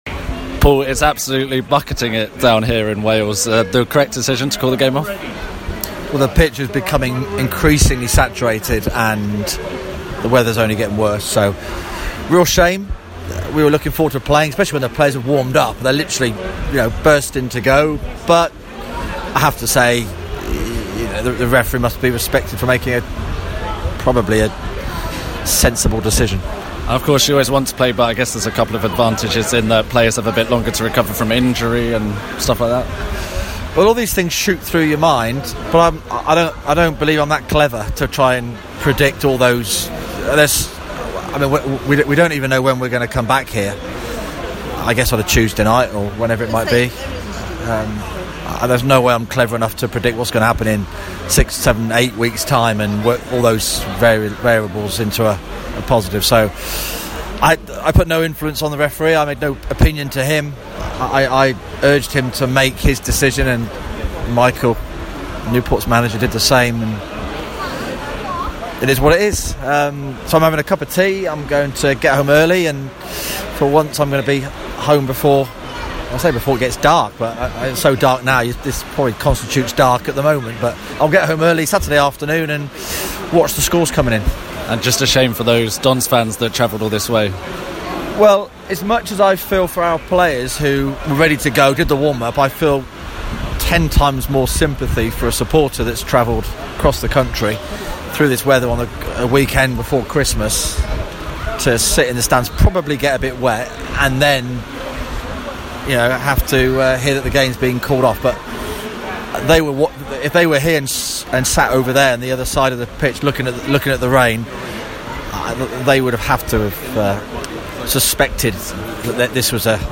MK Dons boss Paul Tisdale has spoken to MKFM after today's match away at Newport County was called off.